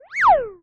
beep_zap_fun_03.wav